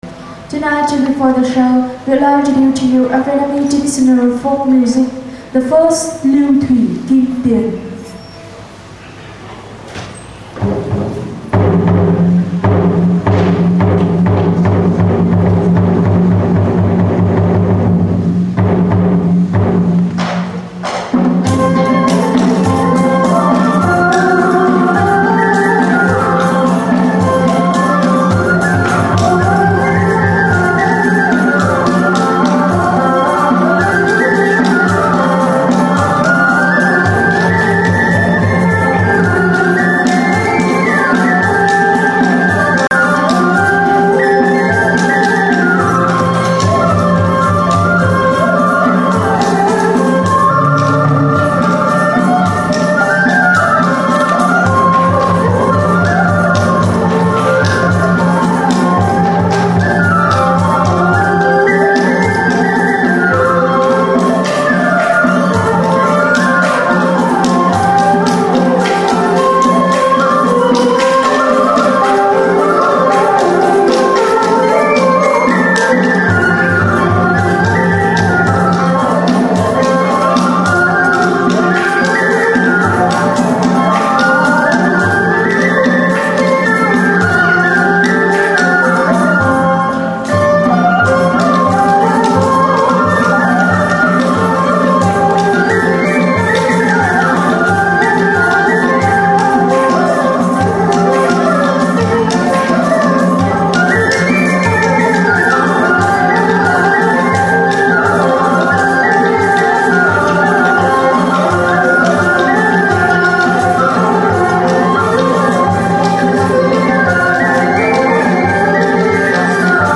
[Nb: l'extrait proposé en téléchargement ci-dessous fait exception à la règle puisqu'il s'agit d'une musique folklorique servant d'introduction au spectacle.]
Le spectacle est si réputé qu'il fait salle comble pratiquement tous les soirs, cela constitue donc de très mauvaises conditions pour filmer...
Toutefois, nous avons pu filmer correctement les musiciens jouant un morceau folklorique très connu en prélude au spectacle.
La formation comporte 7 musiciens qui jouent des instruments suivants (de gauche à droite, puis d'avant en arrière: 1 idiophone Xinh Tien, 1 monocorde Dan Bau, 1 vièle Dan Nhi Cao, 1 Dan Nguyêt (ou "moon guitar"), 1 cithare Dan Tranh, 1 flûte en bambou ou en bois dont nous ignorons le nom et les caractéristiques et enfin des 3 tambours cylindriques dont nous ignorons, là aussi, le nom.
theatre_des_marionnettes_sur_l_eau_hanoi.wav